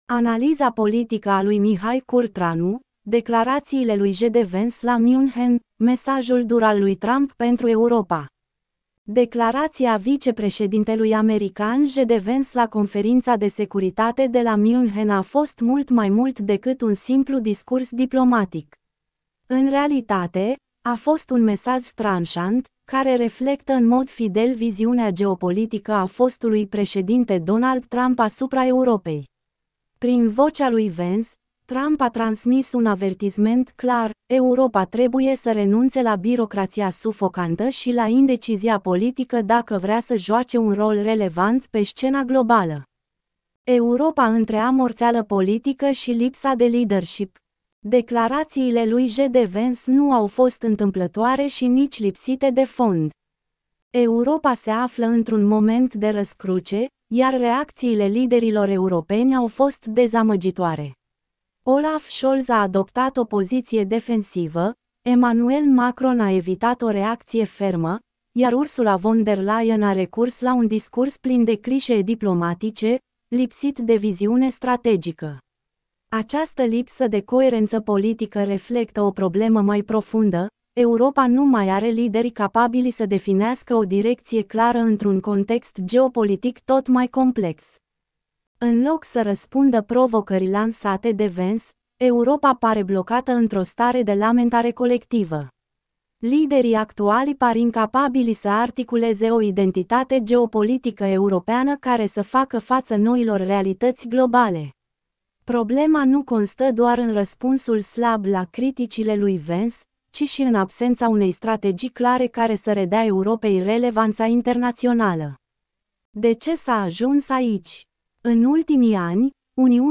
Get in touch with us Ascultă articolul Declarația vicepreședintelui american JD Vance la Conferința de Securitate de la München a fost mult mai mult decât un simplu discurs diplomatic.